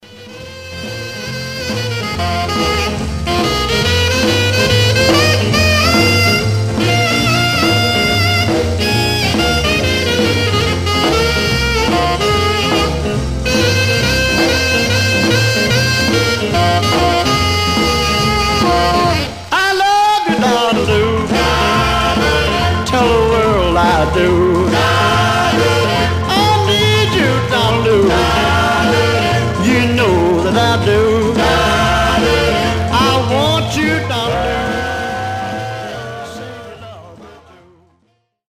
Surface noise/wear
Mono
Teen